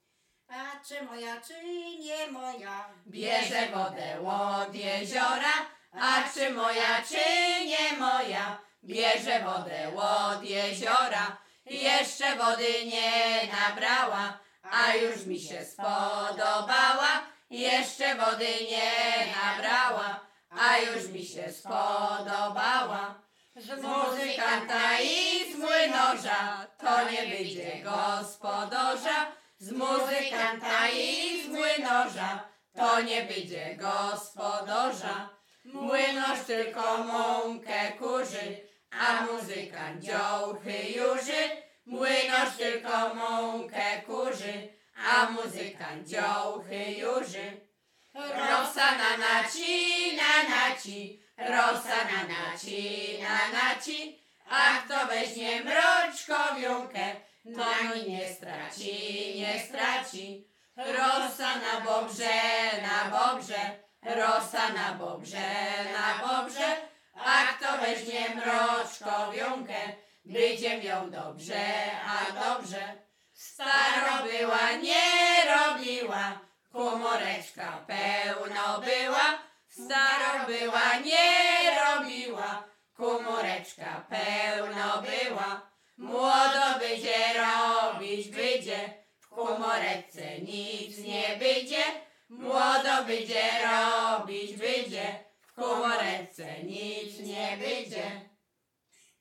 Śpiewaczki z Mroczek Małych
Sieradzkie
województwo łodzkie, powiat sieradzki, gmina Błaszki, wieś Mroczki Małe
liryczne miłosne